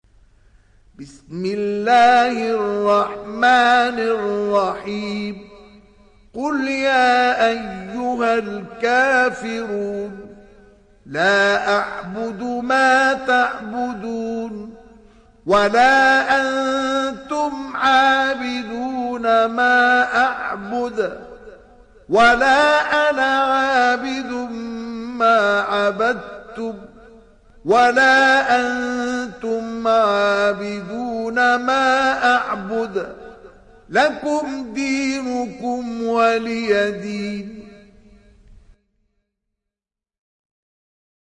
دانلود سوره الكافرون mp3 مصطفى إسماعيل روایت حفص از عاصم, قرآن را دانلود کنید و گوش کن mp3 ، لینک مستقیم کامل